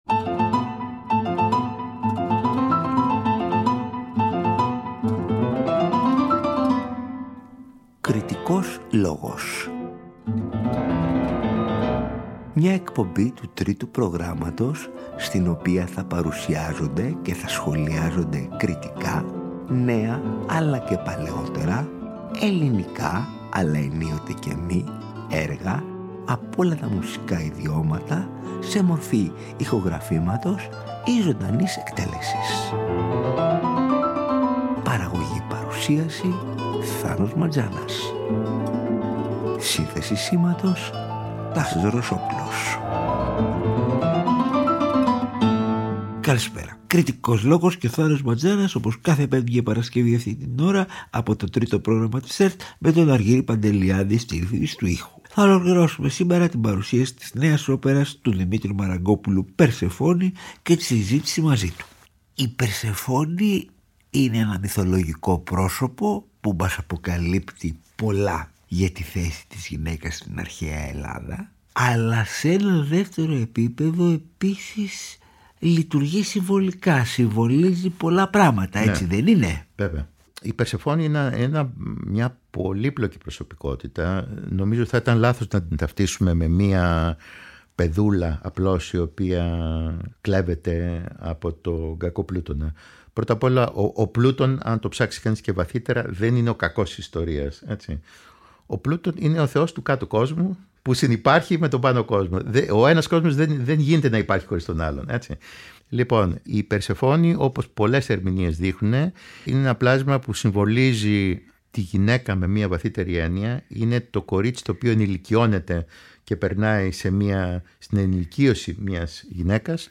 Καλεσμένος στο στούντιο του Τρίτου Προγράμματος ο ίδιος ο συνθέτης, στο πλαίσιο της εκπομπής «Κριτικός Λόγος» την Πέμπτη 7 και την Παρασκευή 8 Μαρτίου 2024, στις 18.00